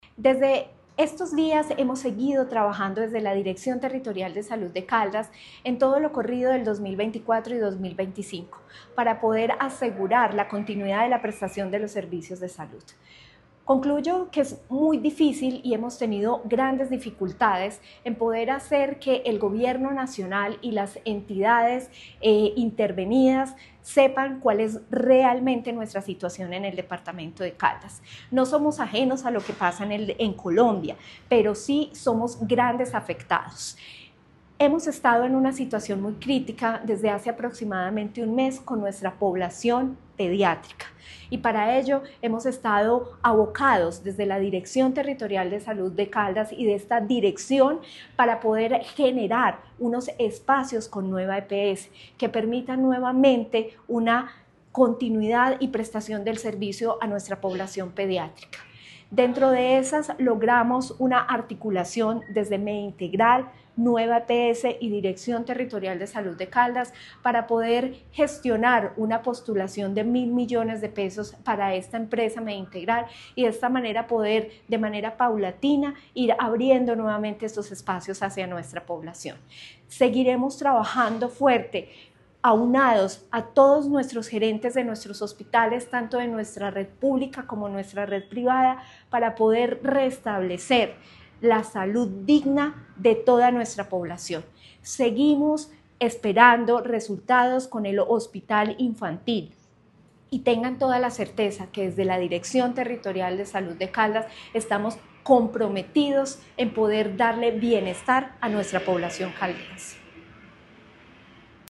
Natalia Castaño Díaz, directora de la DTSC.
AUDIO-NATALIA-CASTANO-DIAZ-DIRECTORA-DTSC-1.mp3